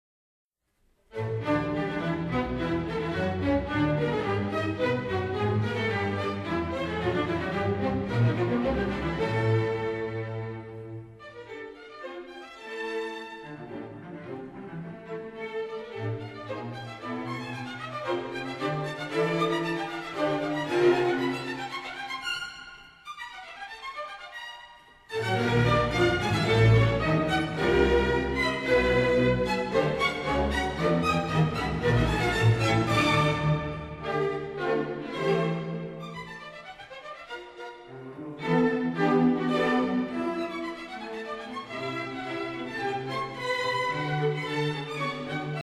Doch es gab wenig Applaus für die Zwölftonmusik.